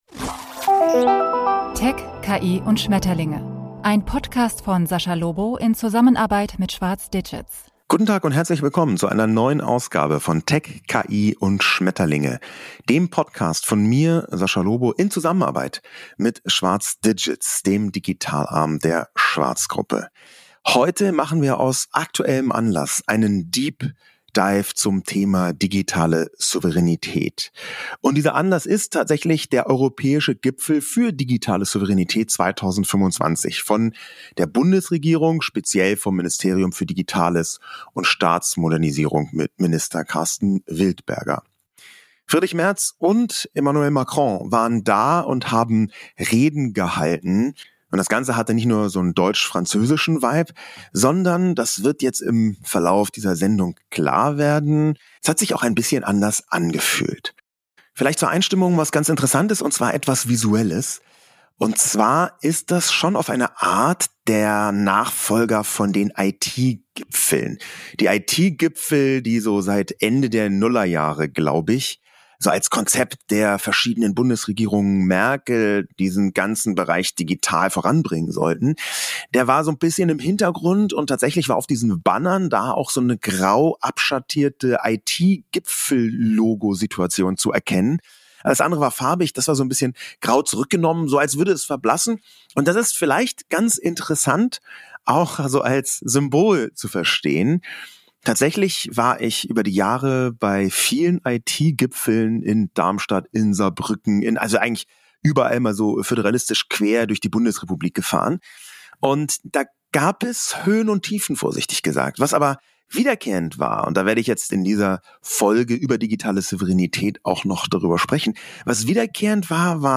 auf dem Europäischen Gipfel zur Digitalen Souveränität 2025 mit Spitzen aus Politik, Wirtschaft und Tech